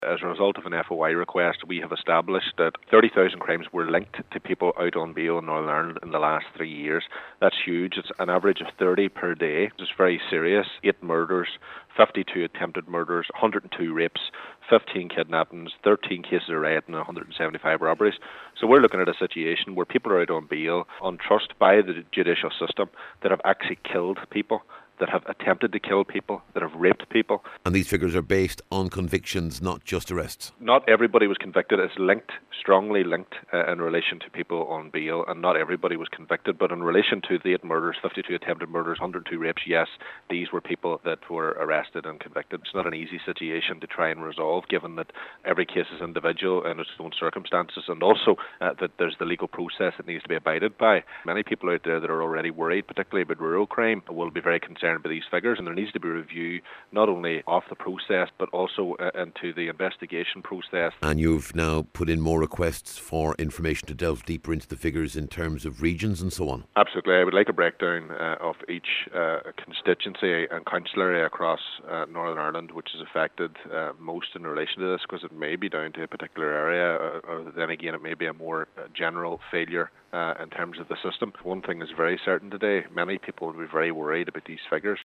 He says this trend must be urgently assessed…………